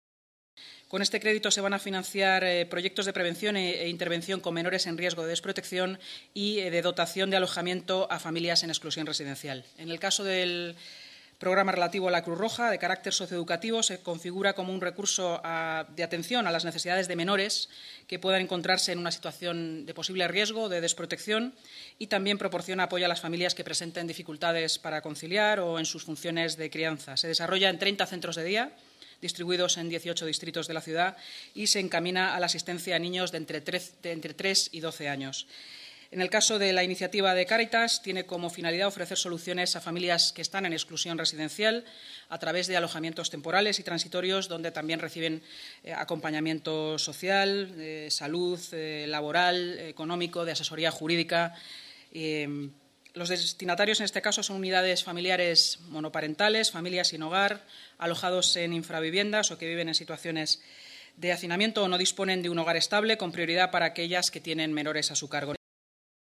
Nueva ventana:Inma Sanz, vicealcaldesa y portavoz municipal